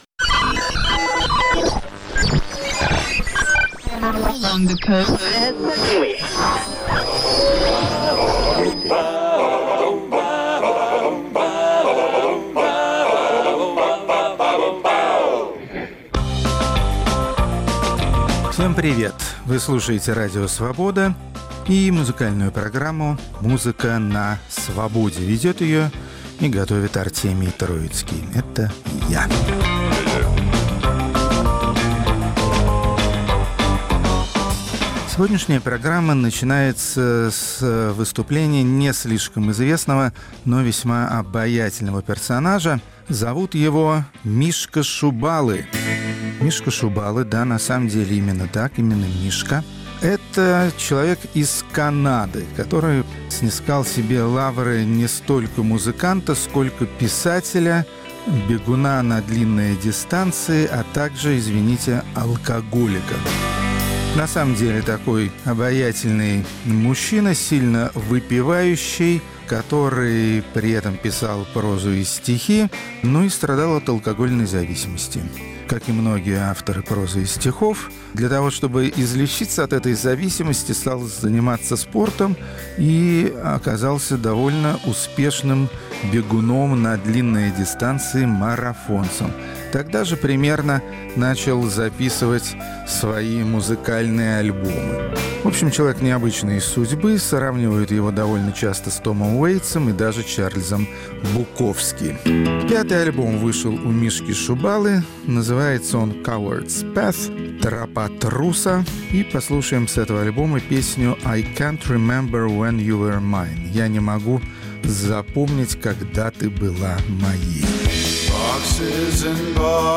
Рок-критик Артемий Троицкий не стесняется признаться в симпатиях к этой самобытной исполнительнице, несмотря на то что в высшую лигу мировой поп-музыки пробиться ей пока не удалось.